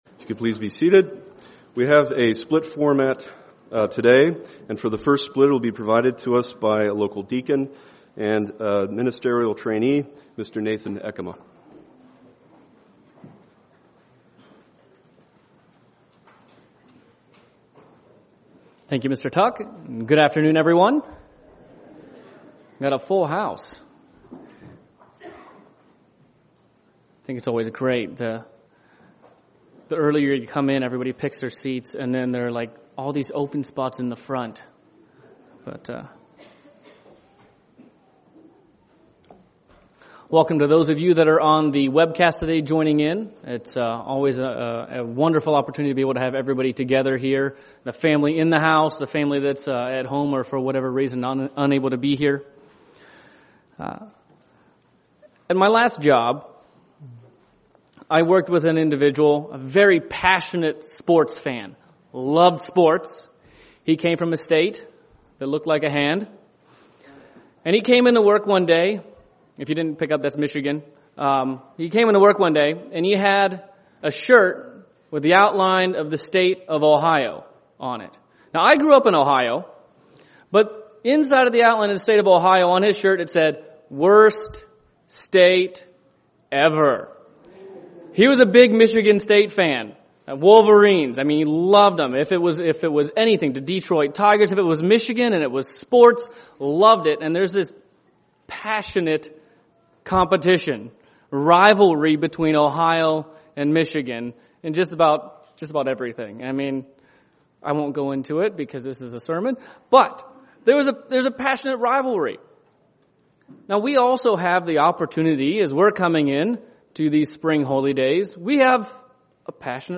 In this sermon we will analyze how we assess sin and then compare that to what is found in God's Word.